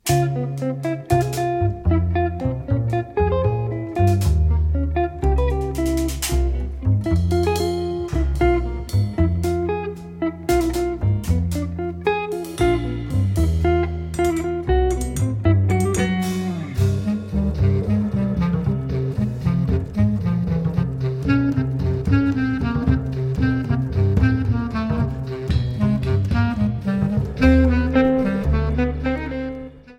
clarinet, bass clarinet, tenor saxophone
electric guitar
double bass
drums
Recorded on April 15, 2018, at Tracking Room, Amsterdam.